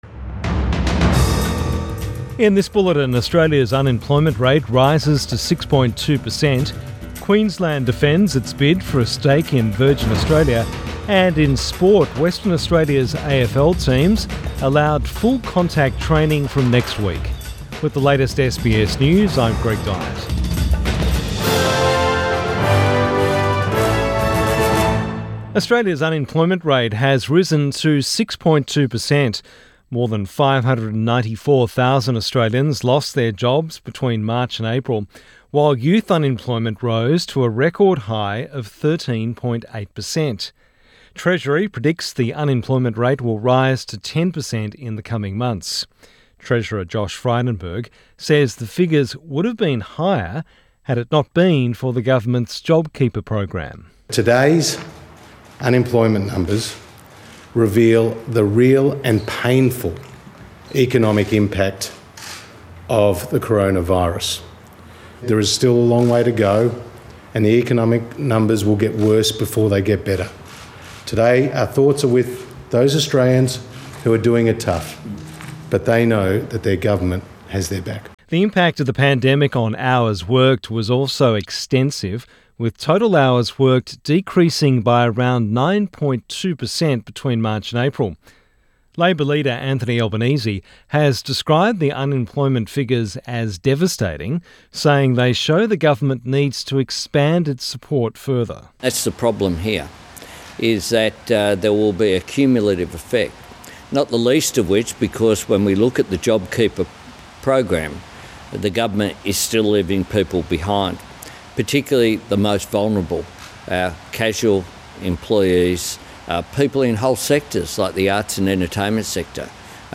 PM bulletin 14 May 2020